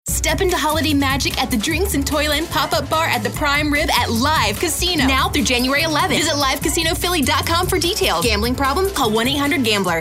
animated, confident, cool, high-energy, perky, retail, upbeat, young adult